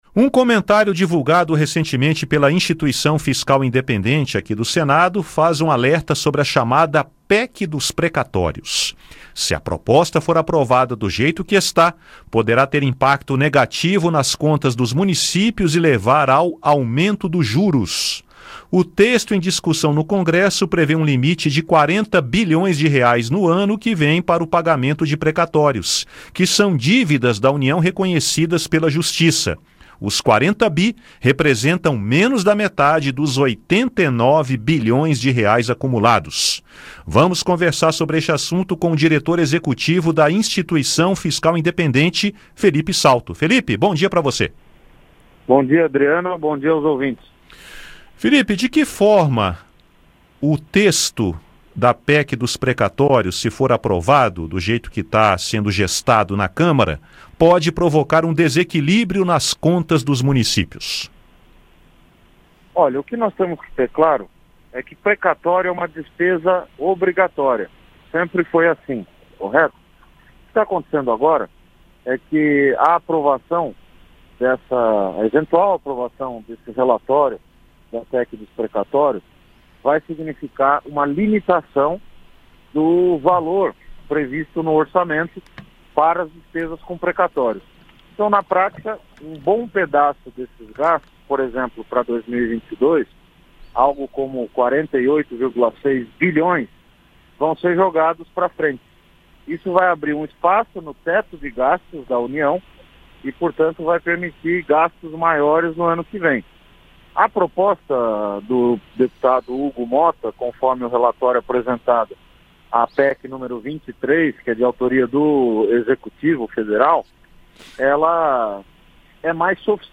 Entrevista: PEC dos Precatórios pode provocar desequilíbrio nas contas dos municípios